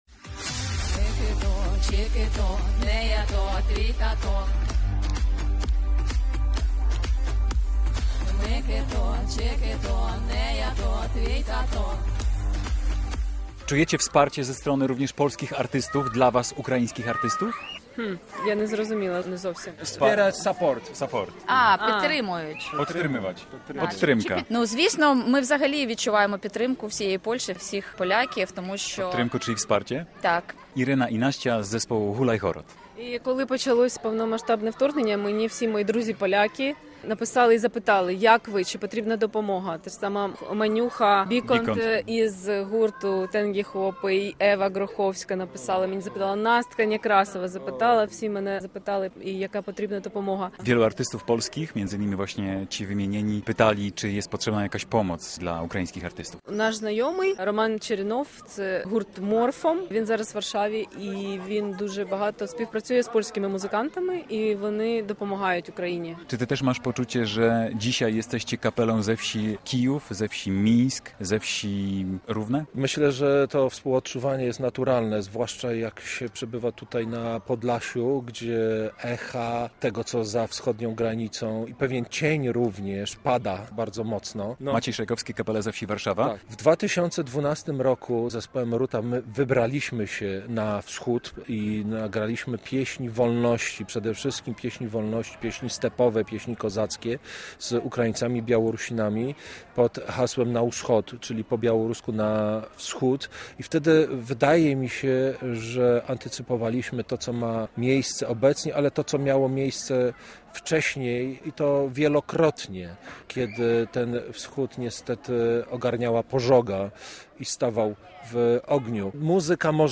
Festiwal Czeremcha Wielu Kultur i Narodów cz. 2 - relacja